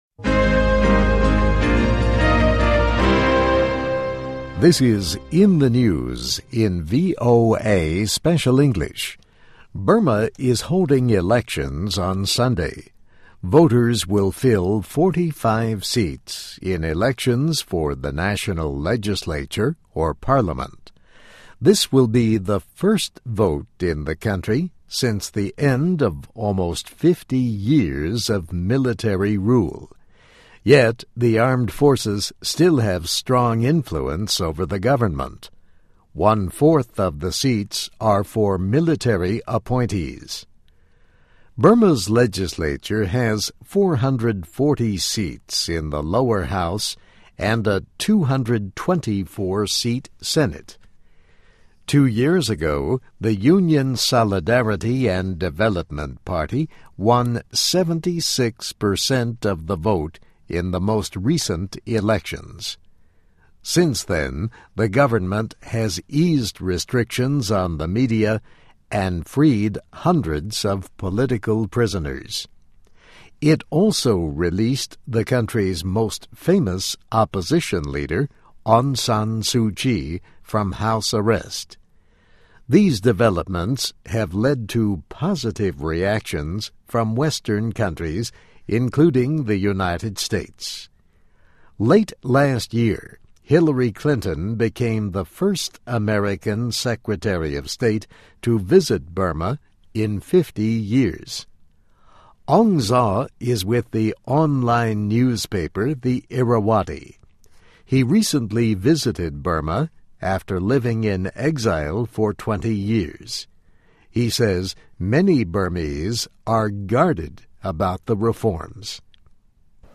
VOA慢速英语 - 缅甸议会选举将成为改革试金石